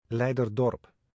Leiderdorp (Dutch pronunciation: [ˌlɛidərˈdɔr(ə)p]